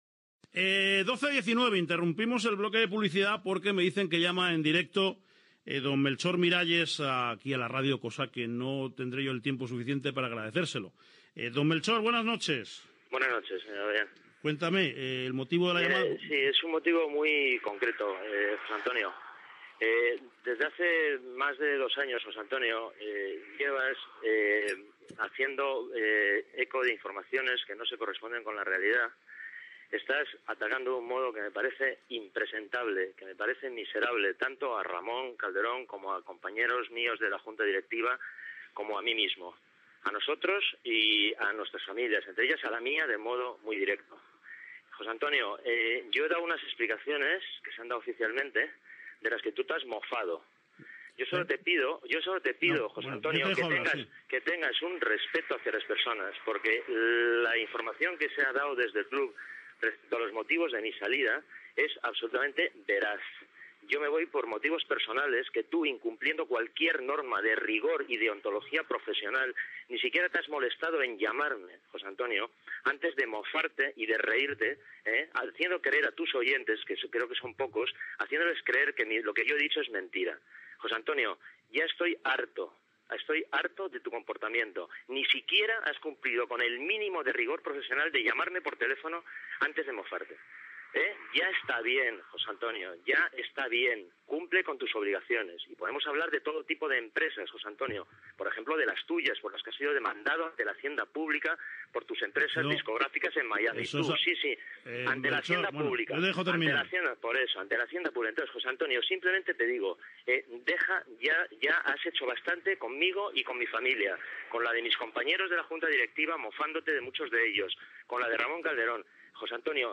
intervenció telefónica del periodista Melchor Miralles
Gènere radiofònic Esportiu